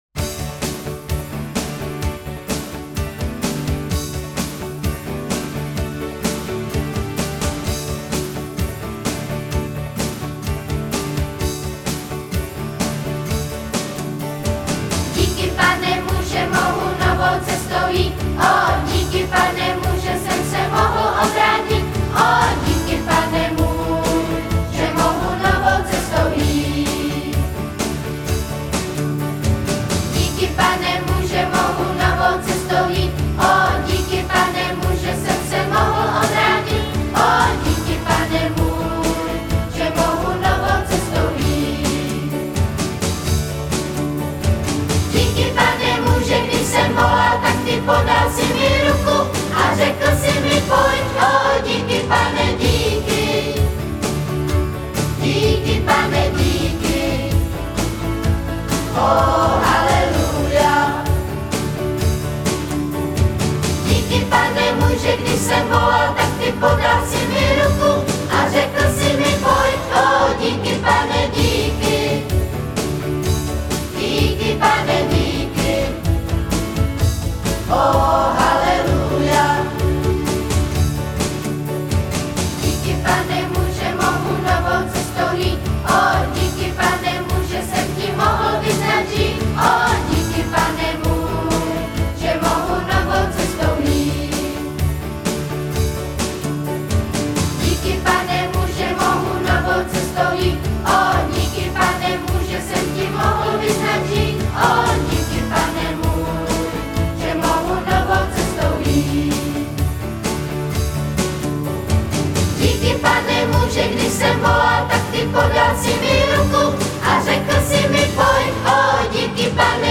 Křesťanské písně